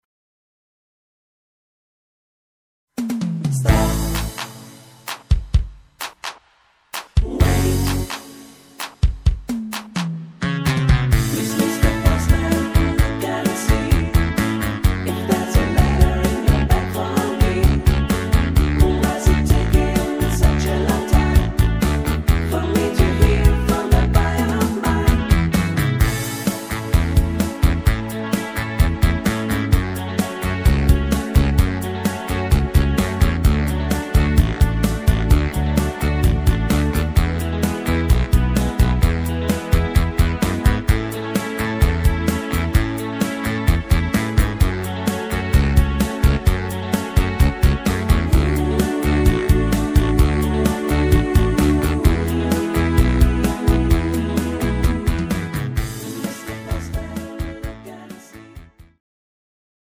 Buy Playback abmischen Buy